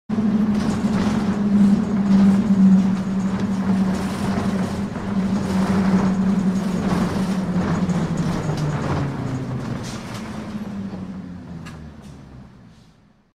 bus-stop.mp3